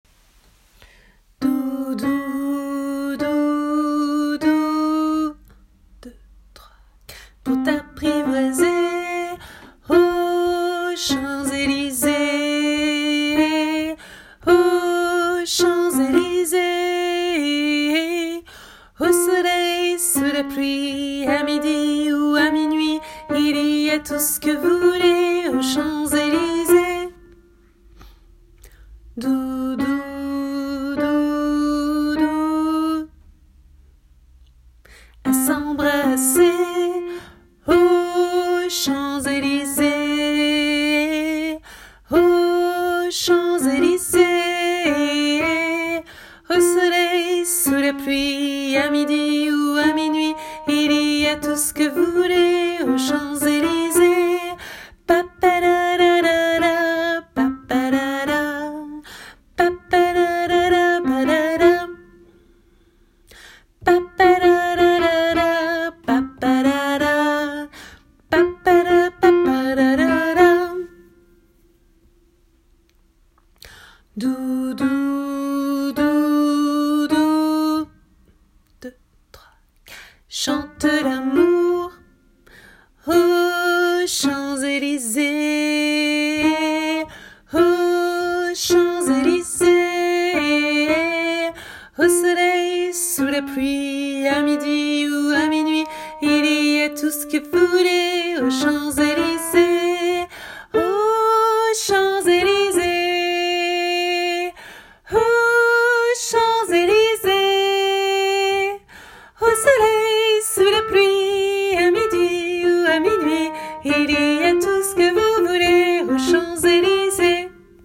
MP3 versions chantées (les audios sont téléchargeables)
Alto